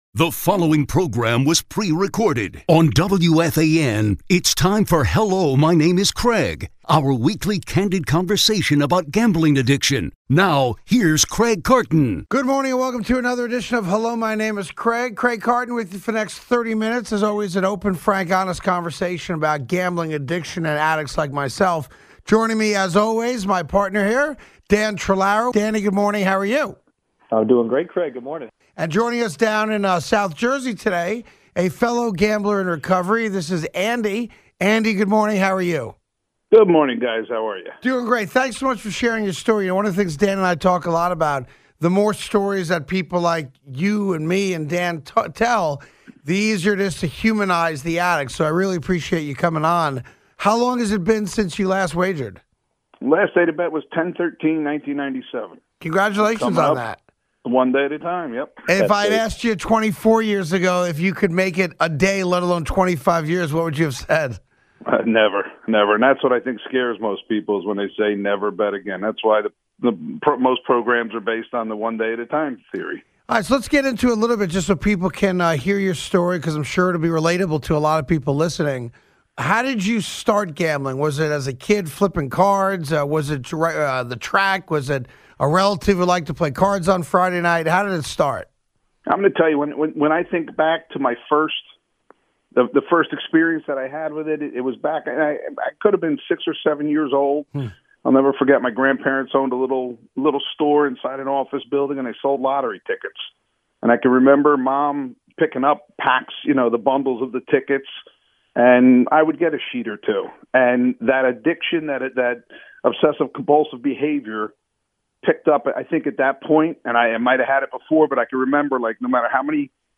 A WEEKLY CANDID COVERSATION ON GAMBLING ADDICTION.